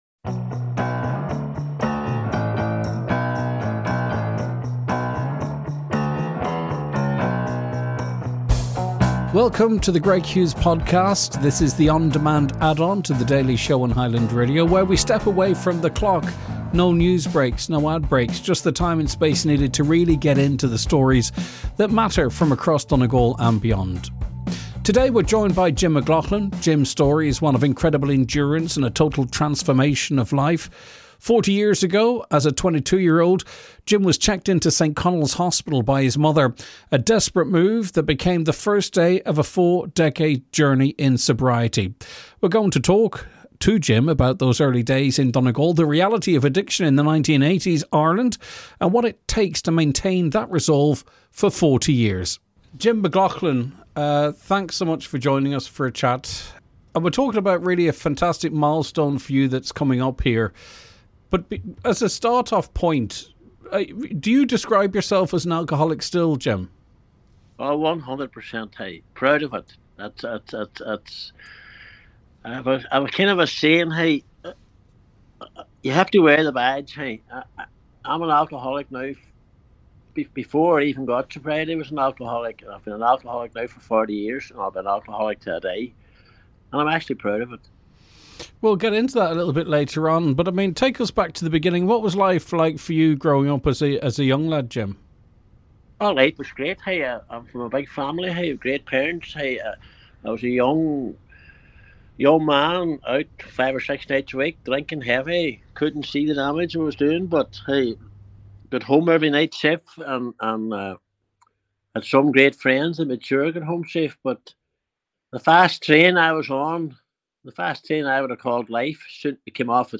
this podcast series features long-form, unfiltered conversations with the people behind the stories.